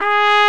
TRUMPET 2 02.wav